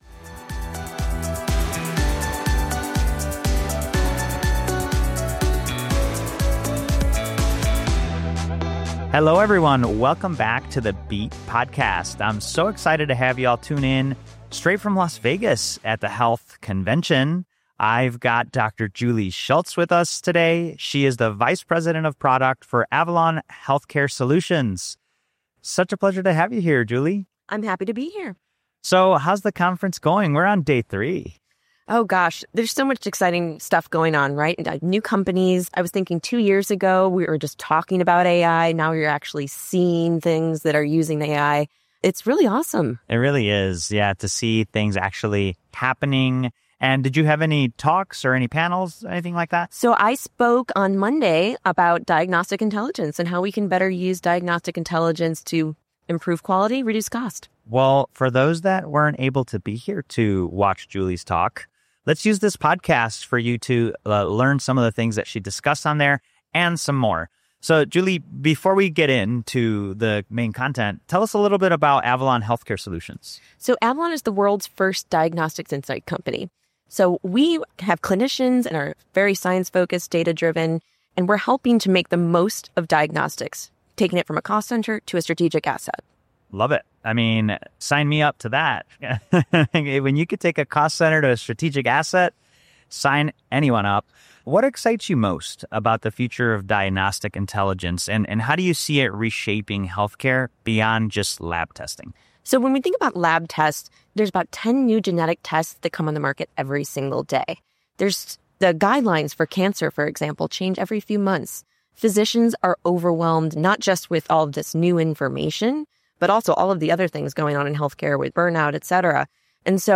The Beat, powered by HLTH, is a weekly interview series dedicated to paving a better path forward for the future of health. Each week a variety of hosts bring you authentic conversations with prominent thought leaders.